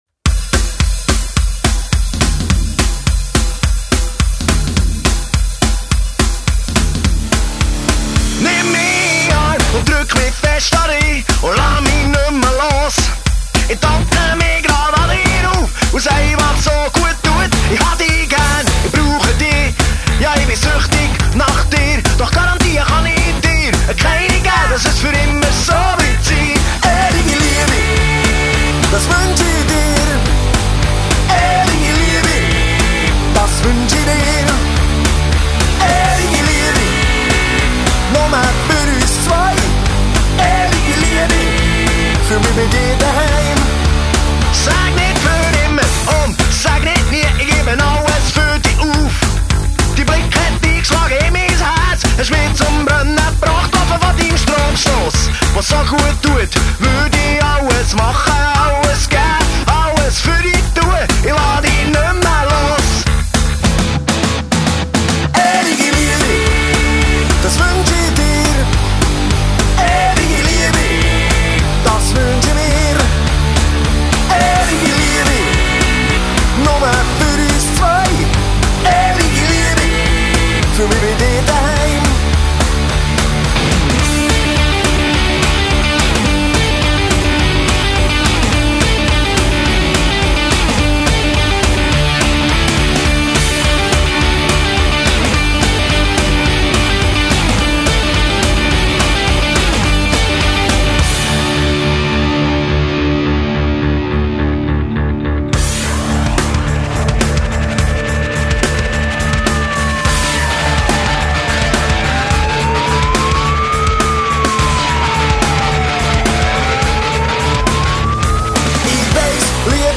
Swiss party hits.